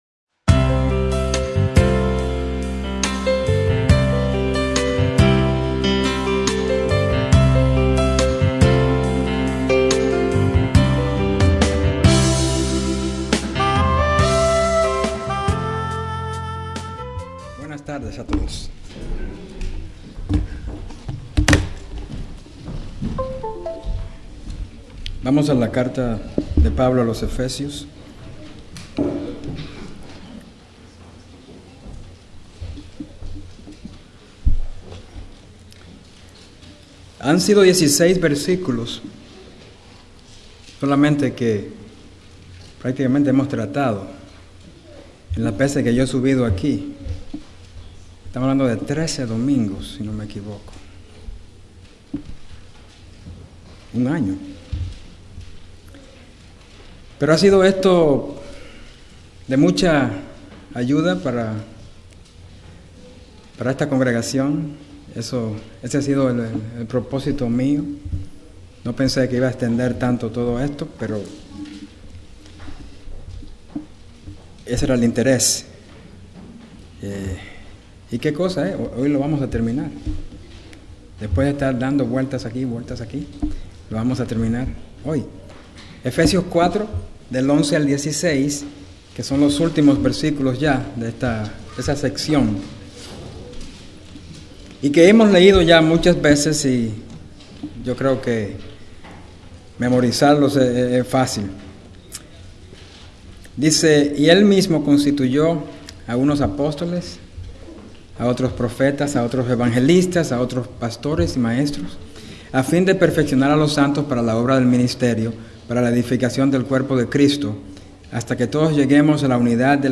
Templo Bíblico Providence